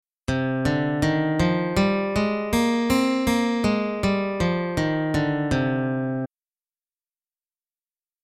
Example 2 – C Minor Scale (Eighth Position) Using Alternate Picking
In this example, you will play one octave of the C Minor Scale in the eighth position.  Three notes are played per string, which will increase the difficulty.
C Minor Scale
cminorscale.mp3